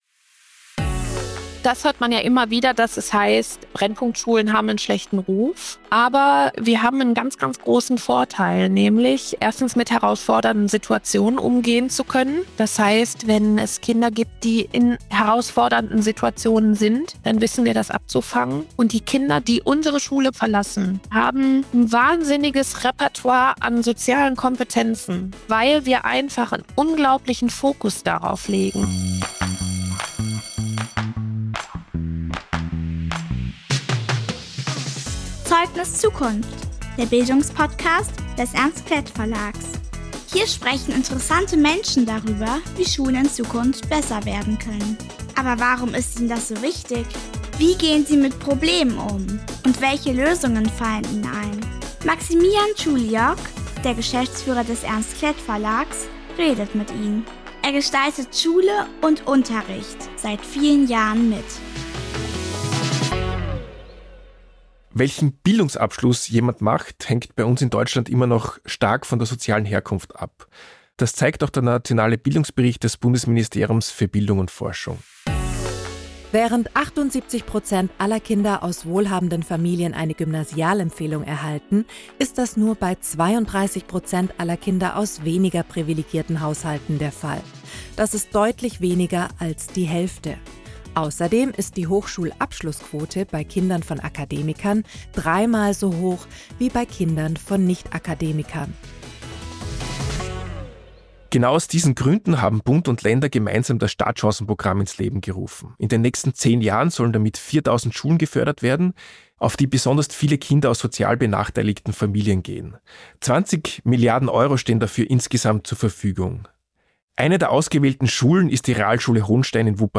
Ein ehrliches Gespräch über Beziehungsarbeit, Geduld, Gelassenheit und den notwendigen Respekt für Menschen, die sich tagtäglich für die Gesellschaft einsetzen.